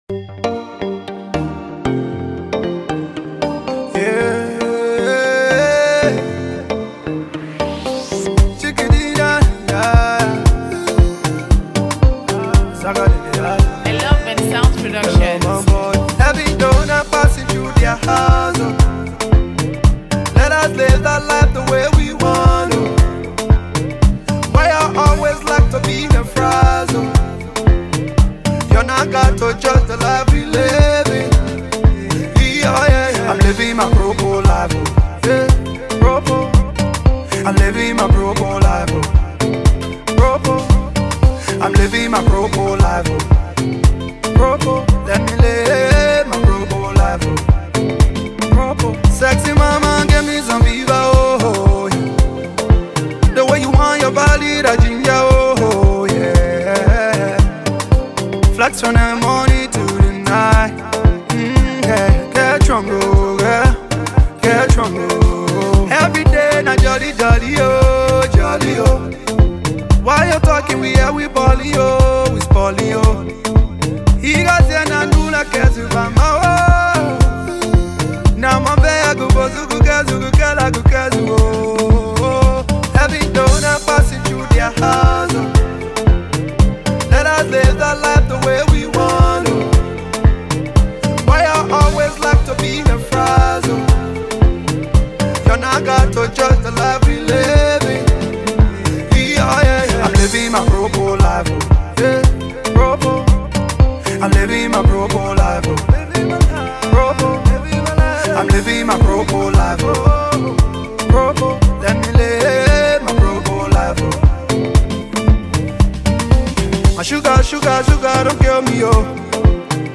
/ Afro-Pop / By